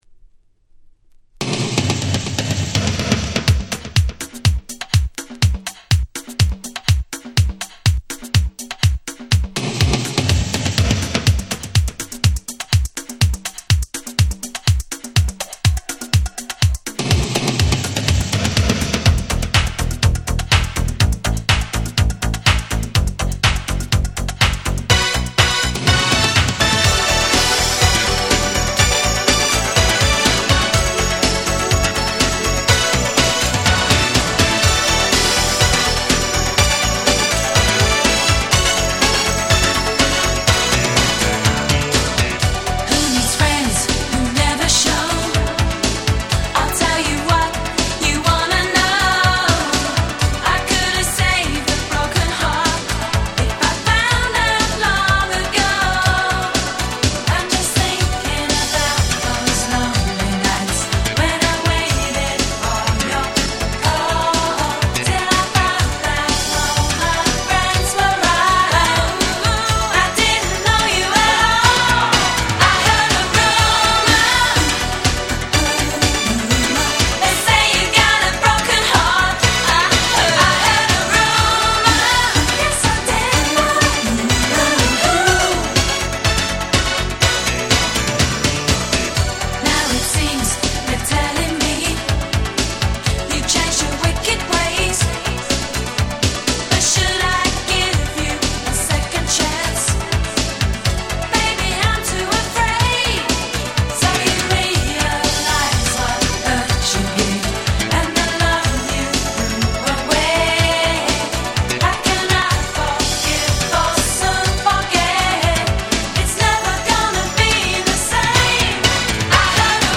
【Media】Vinyl 12'' Single
87' Super Hit Euro Beat / Disco !!